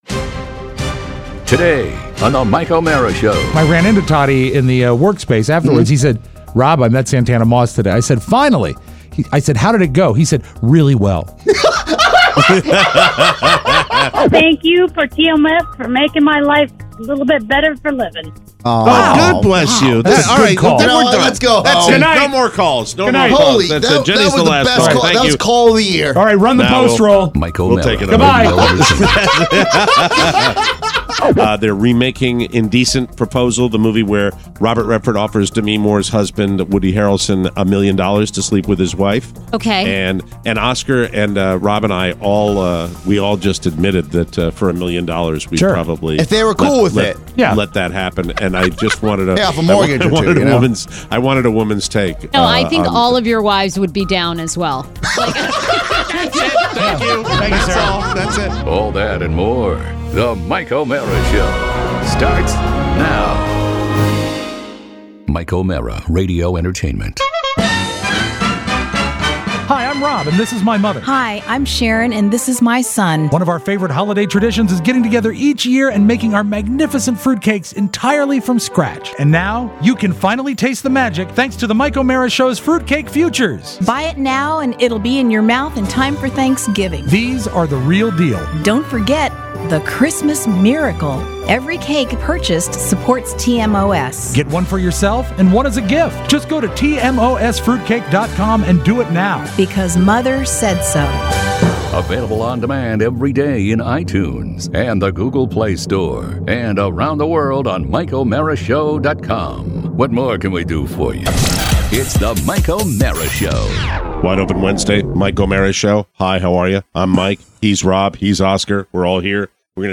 Your calls!